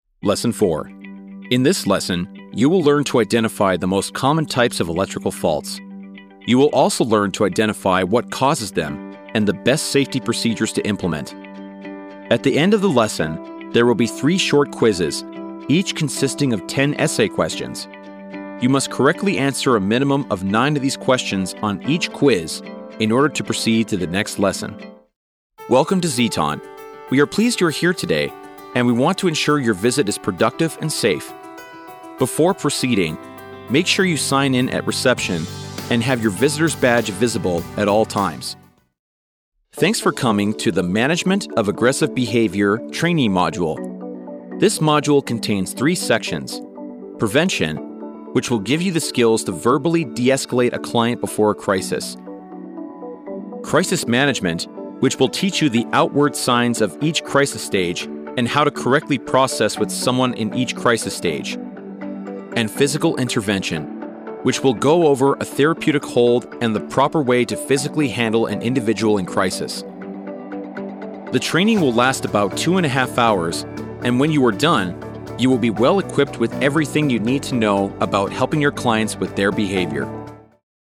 Male
Yng Adult (18-29), Adult (30-50)
Bass-baritone, melodious, articulate and masculine.
E-Learning
0916E-Learning_Demo.mp3